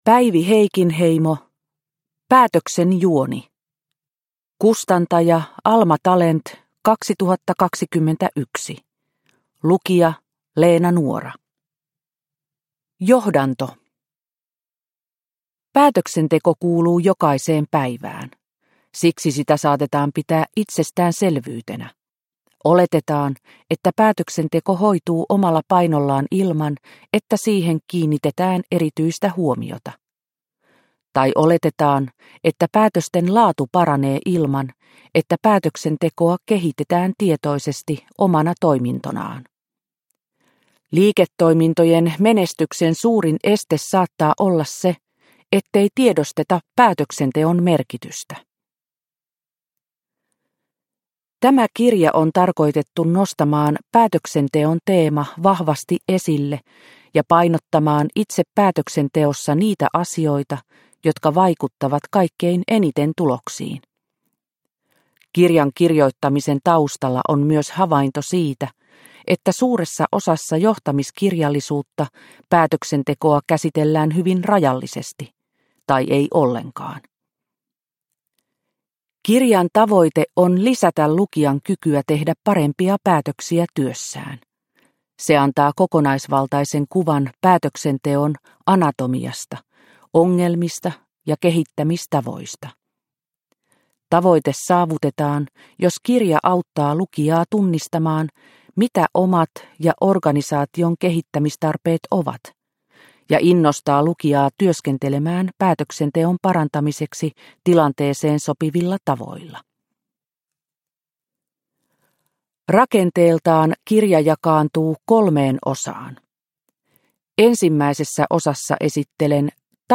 Päätöksen juoni – Ljudbok – Laddas ner